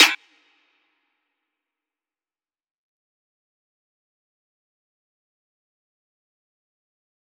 DMV3_Snare 20.wav